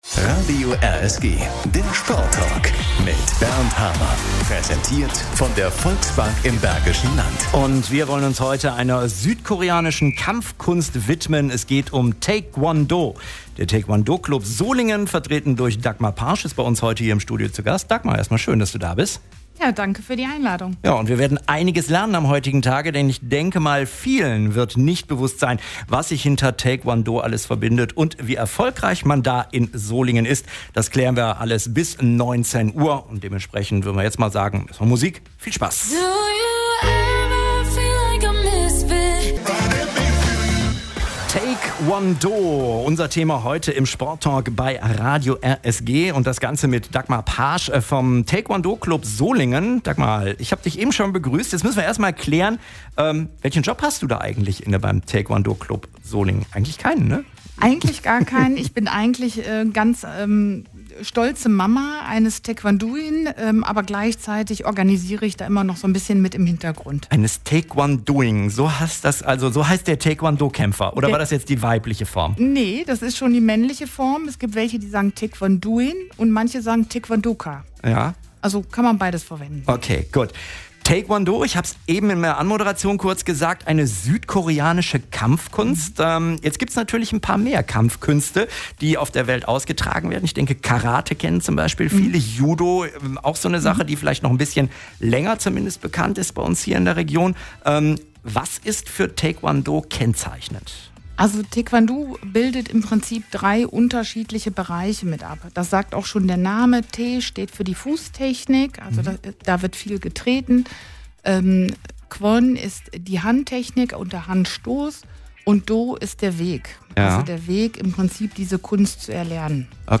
RSG-Sporttalk